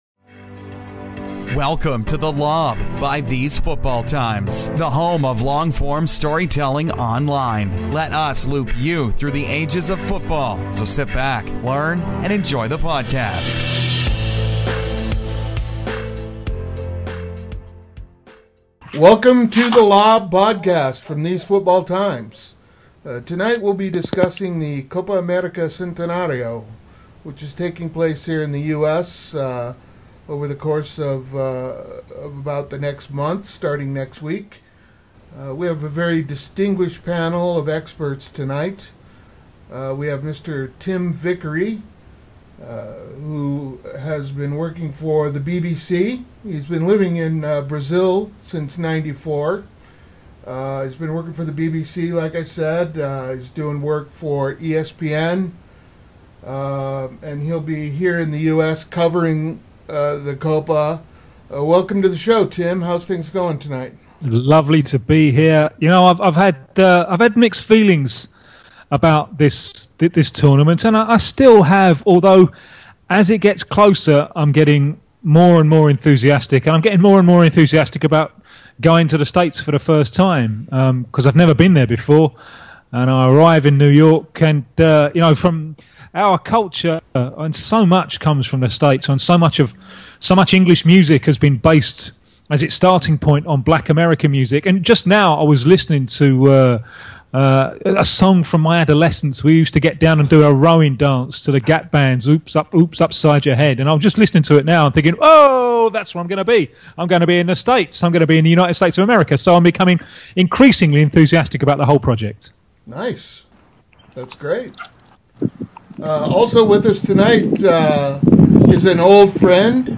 brings you a discussion on the major points ahead of the tournament